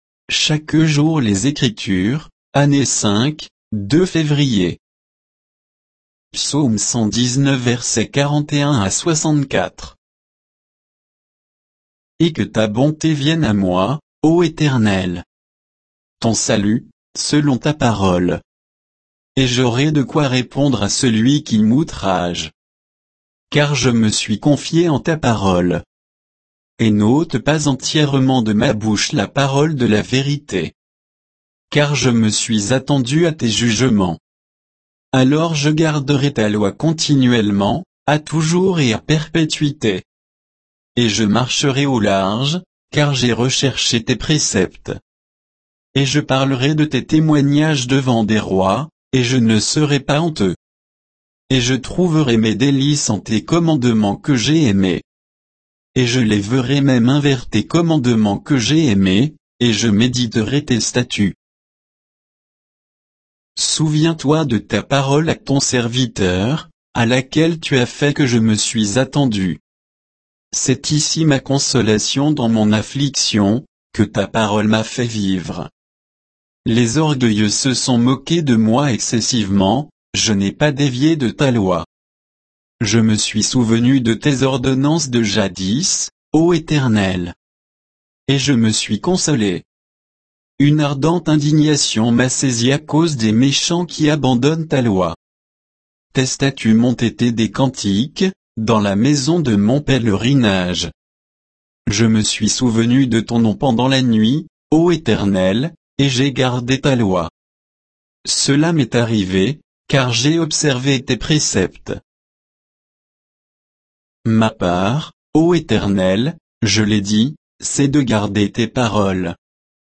Méditation quoditienne de Chaque jour les Écritures sur Psaume 119